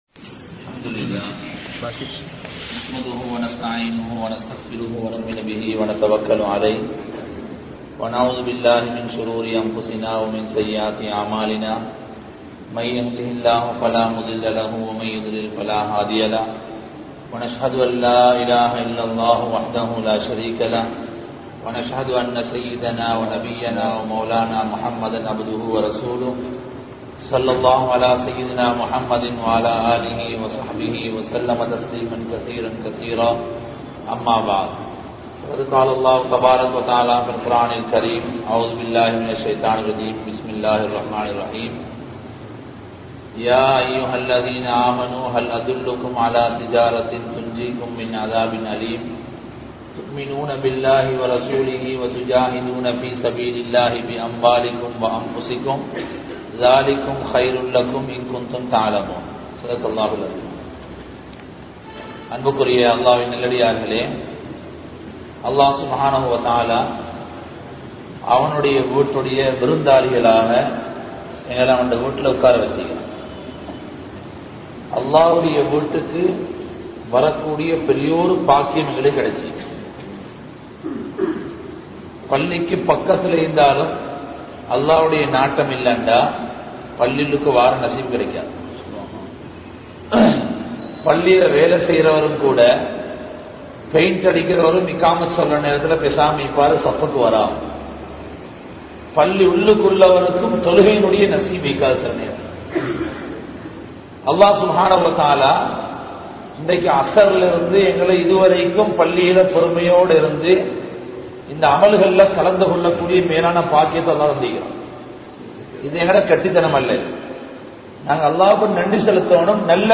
Eemaanudaiya Ulaippu (ஈமானுடைய உழைப்பு) | Audio Bayans | All Ceylon Muslim Youth Community | Addalaichenai
Mawanella, Ganethanna, Rahman Jumua Masjidh